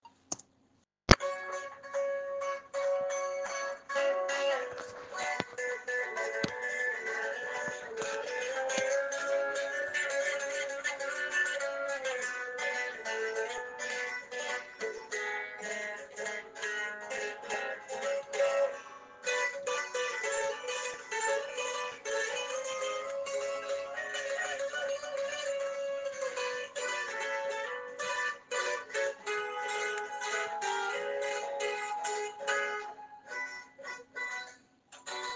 Escucha algunos Beats…
Genere - HipHop - Trap
G#min